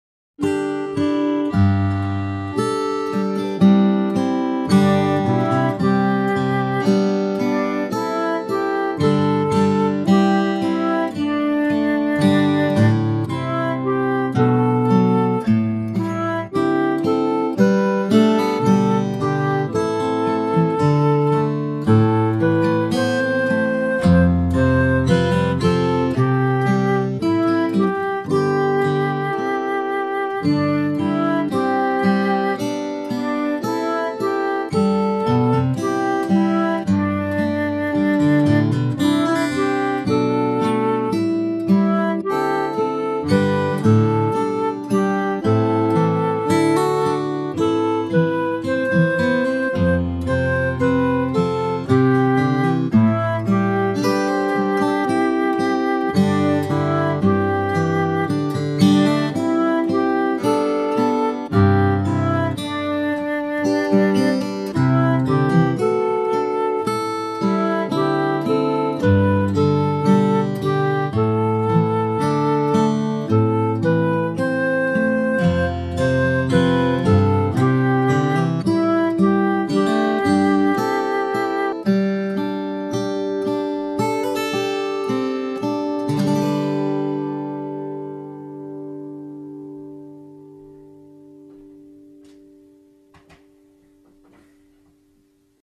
gentle recessional